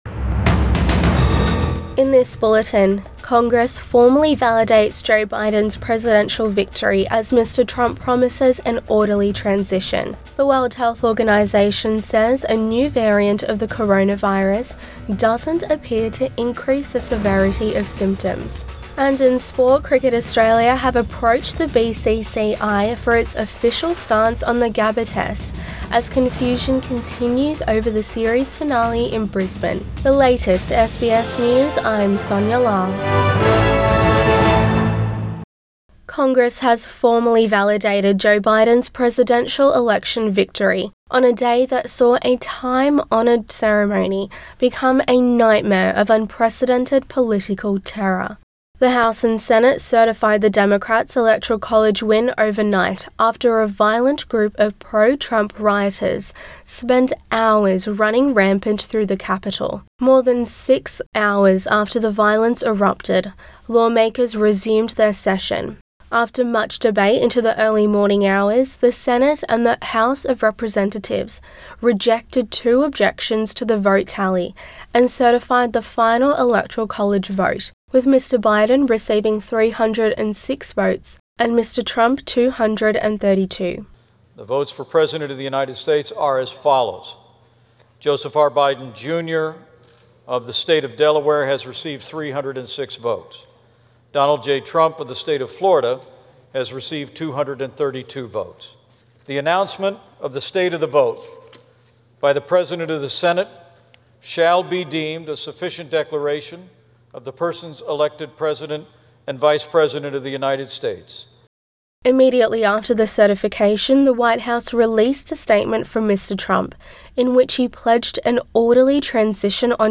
AM bulletin 8 January 2021